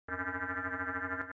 ovni
ovni.mp3